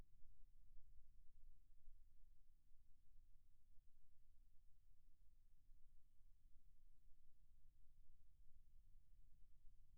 3M Peltor X5A Wind Noise
earmuffs-wind-noise.wav